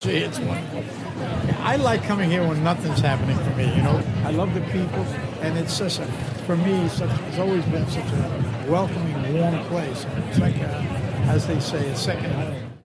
On a windy red carpet for his new film Salome, he told us he loves being in the UK so receiving an award in Britain means a lot.